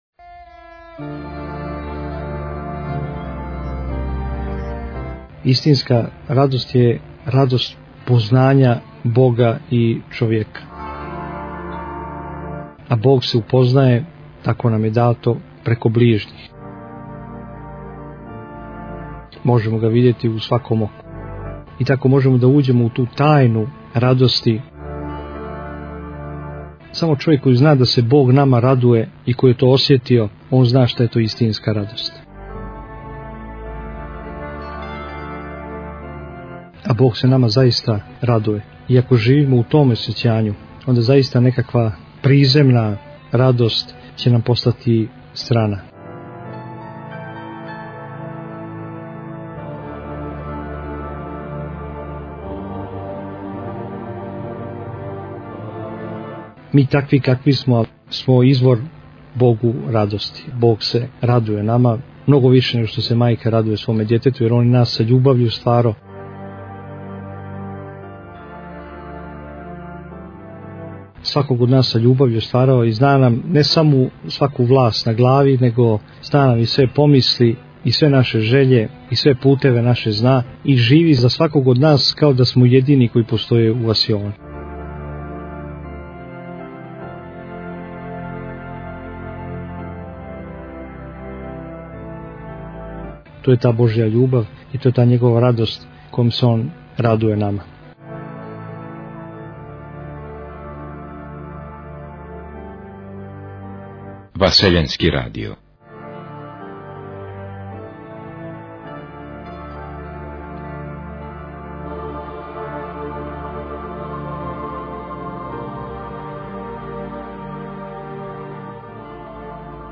Формат: MP3 Mono 11kHz 20Kbps (VBR)